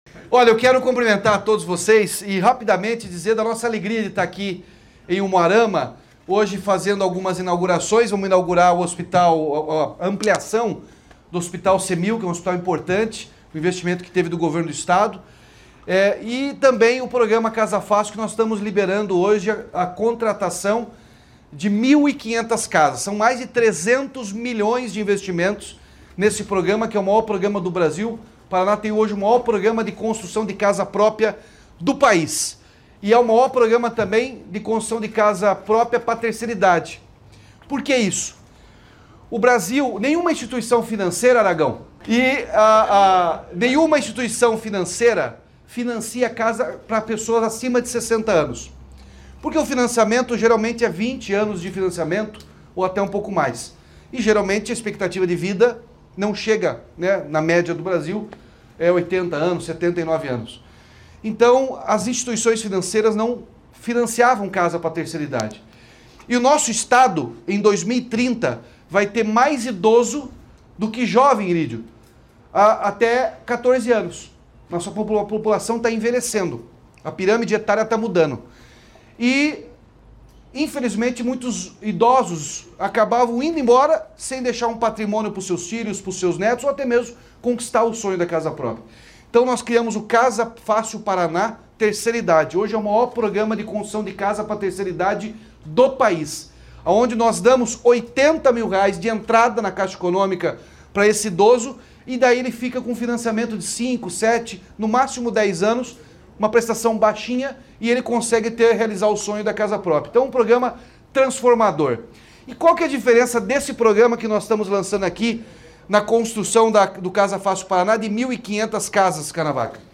Sonora do governador Ratinho Junior sobre o anúncio da construção de bairro planejado com 1.500 casas em Umuarama